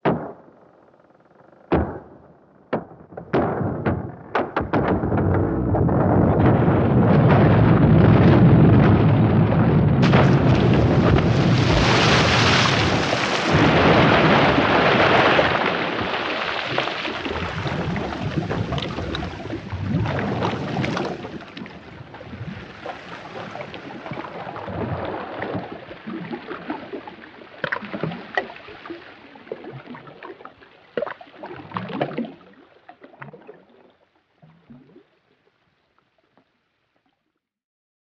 Звук ледокола раскалывающего айсберг